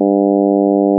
less10bandpass.wav